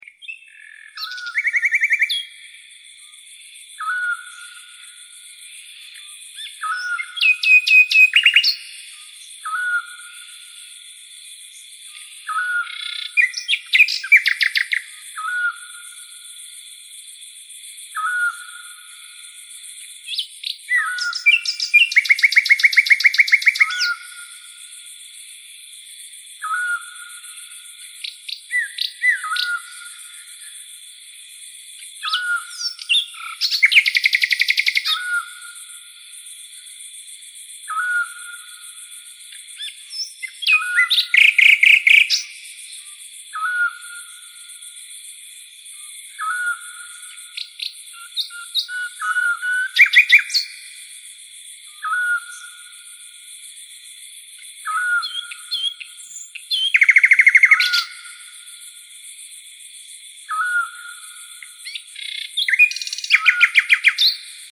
Другие рингтоны по запросу: | Теги: звуки природы